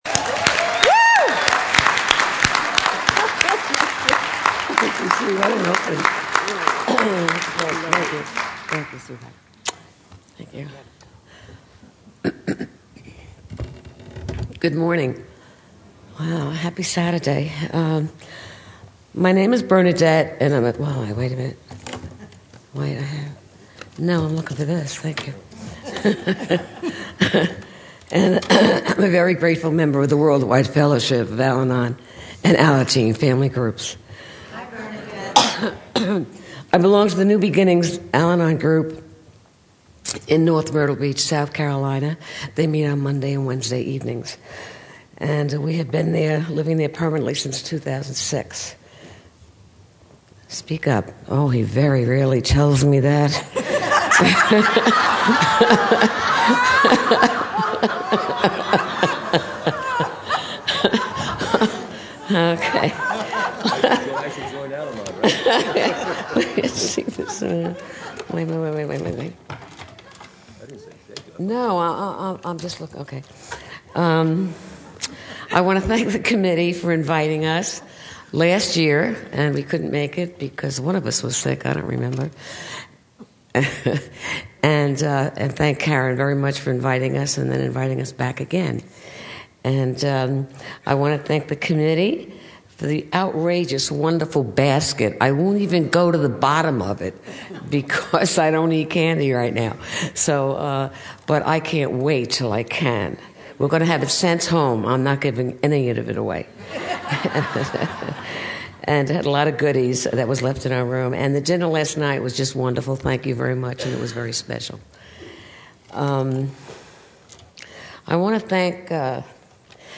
San Diego Spring Roundup 2010